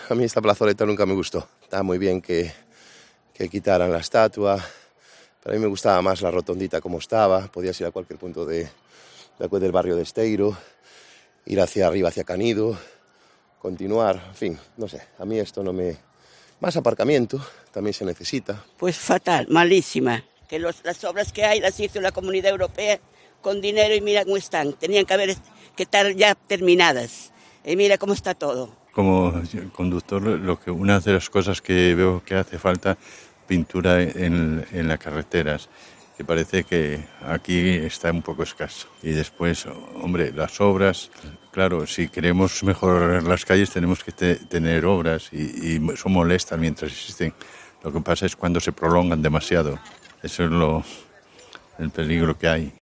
Los ciudadanos opinan sobre las necesidades urbanísticas de Ferrol